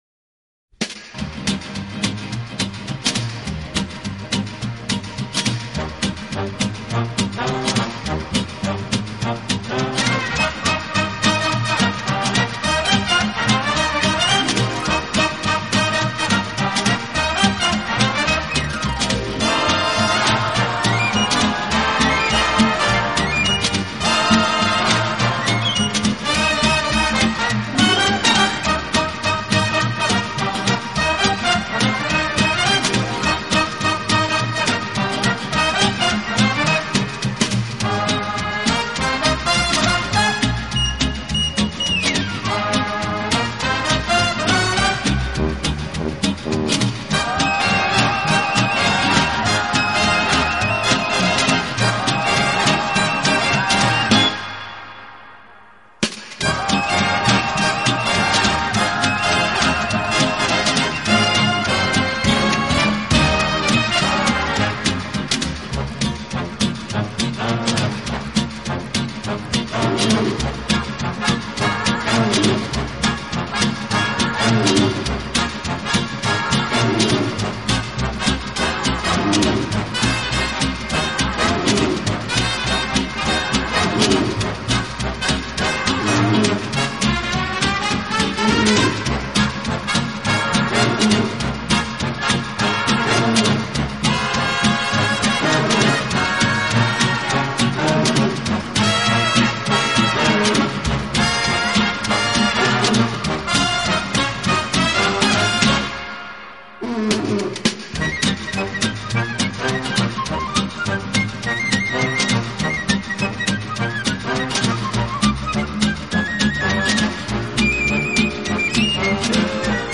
【轻音乐专辑】
回忆。他在60年代以男女混声的轻快合唱，配上轻松的乐队伴奏，翻唱了无数热